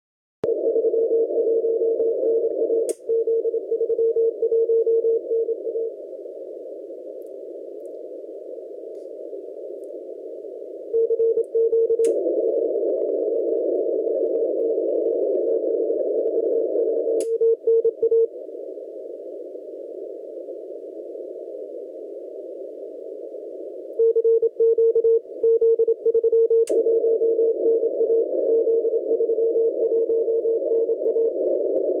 Antenne en kort Cu-tråd med ATU og som RX fasningantenne har jeg viklet en kort helical på en fiskestang.